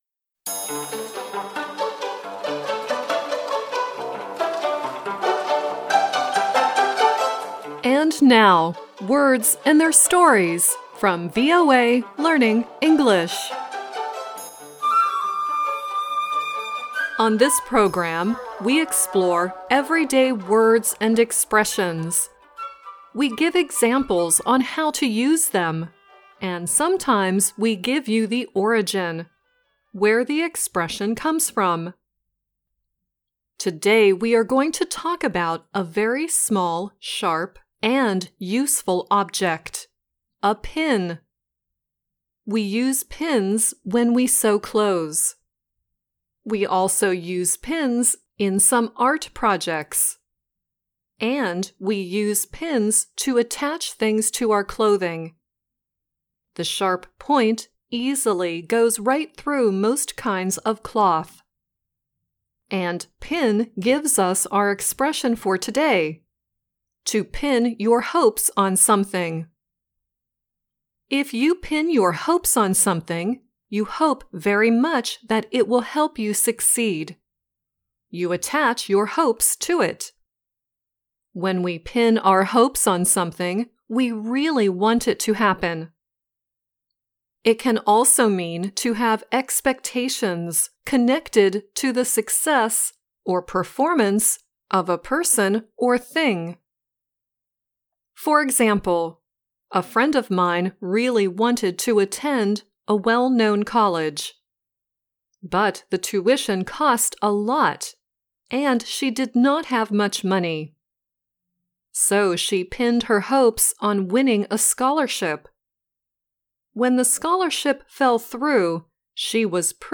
Kalau bisa diusahakan bacanya mirip dengan native speaker dibawah ini, oke..